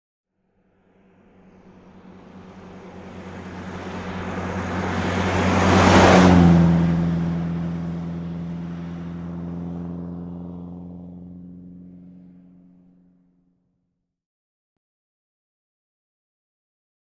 Aston Martin; By, Medium; Steady Engine, Medium By With Some Tire Whine. Medium Perspective. Sports Car, Auto.